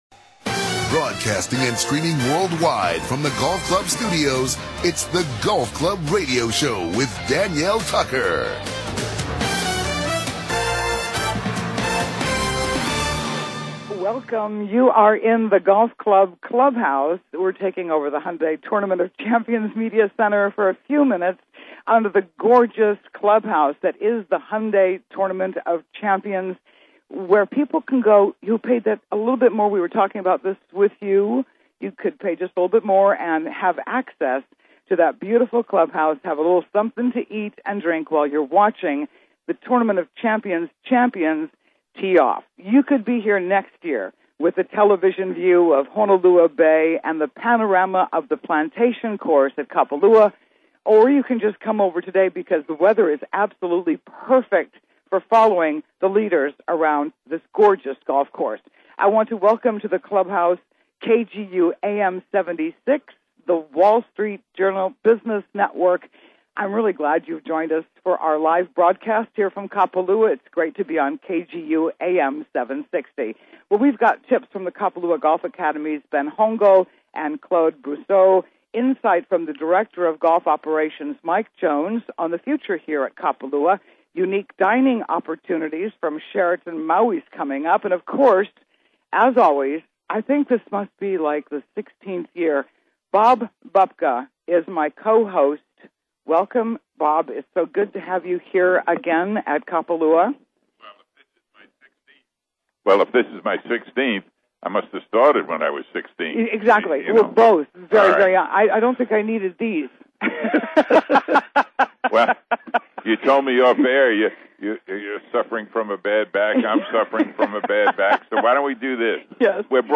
Live from Kapalua The Hyundai Tournament of Champions In the Clubhouse